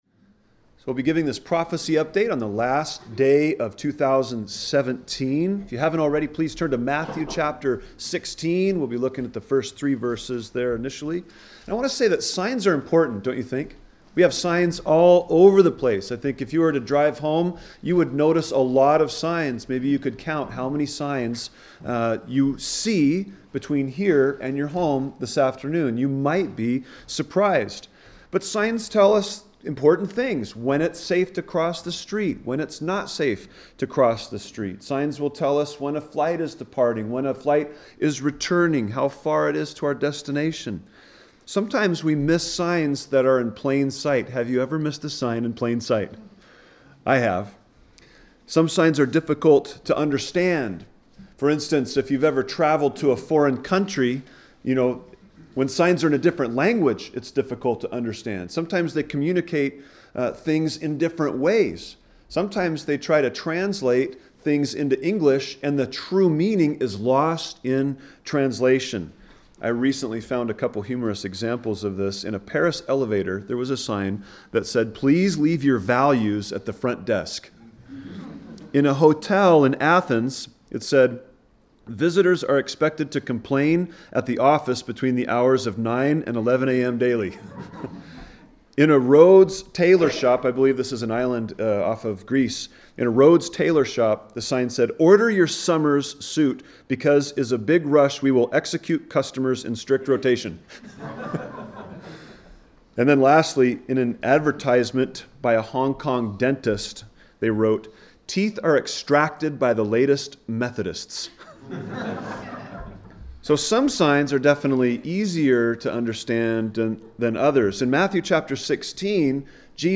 A message from the series "Topical."